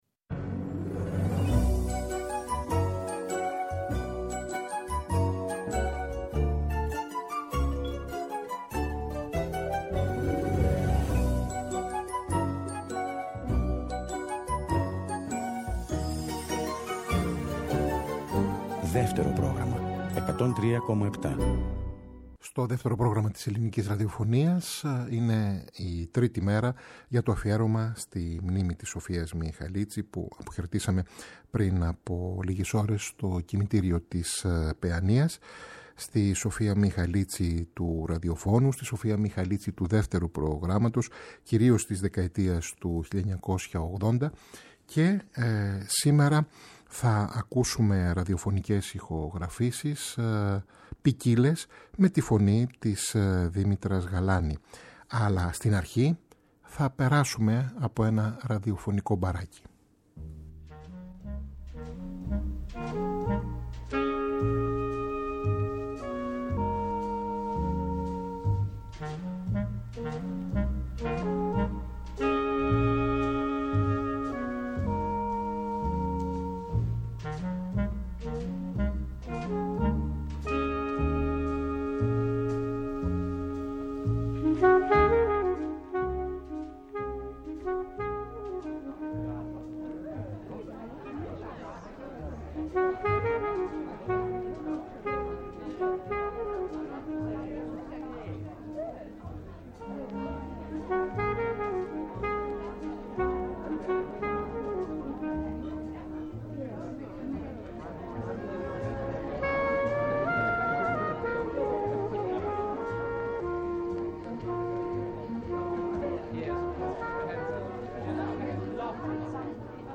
ακούμε τραγούδια
στην κιθάρα